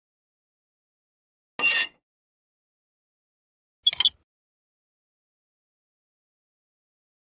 Metal Squeaks
Metal Squeaks is a free sfx sound effect available for download in MP3 format.
yt_KTcTnW6DcxA_metal_squeaks.mp3